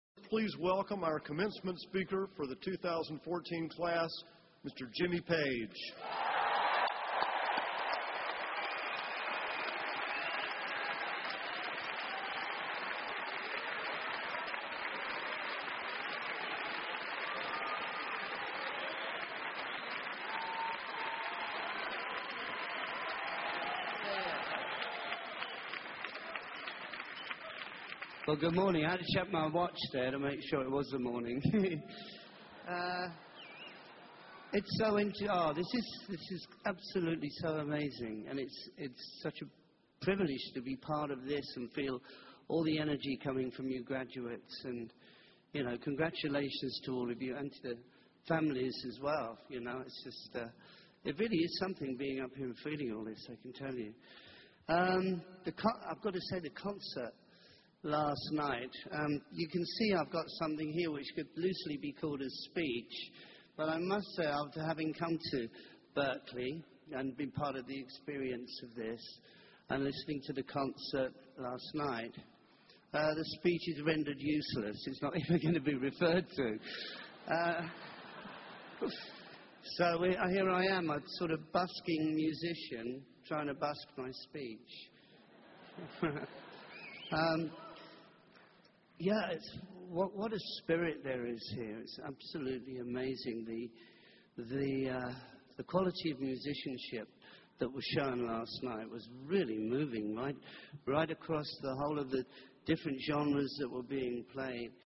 公众人物毕业演讲 第172期:吉米佩吉2014在伯克利音乐学院(1) 听力文件下载—在线英语听力室